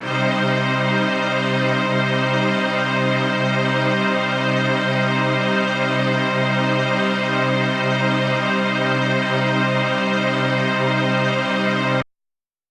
SO_KTron-Ensemble-Cmin.wav